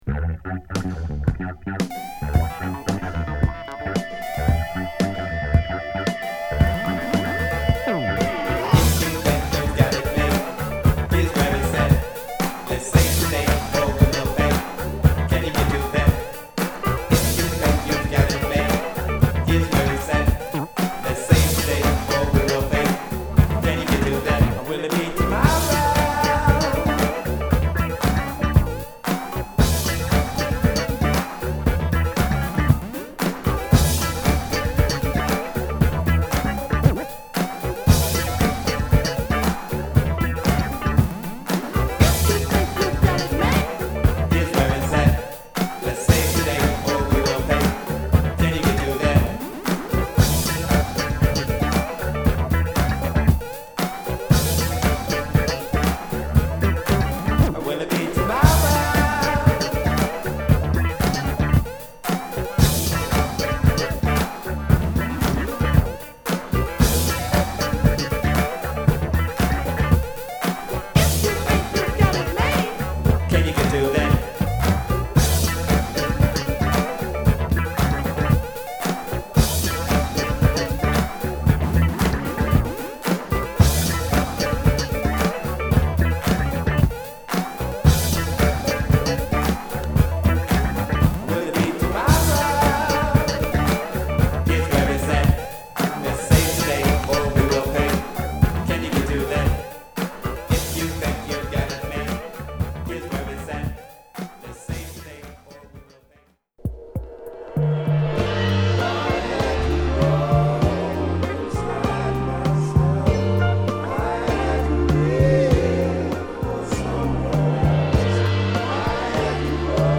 P-Funkの粘着感とこの時代らしい洗練されたサウンドがマッチした好作！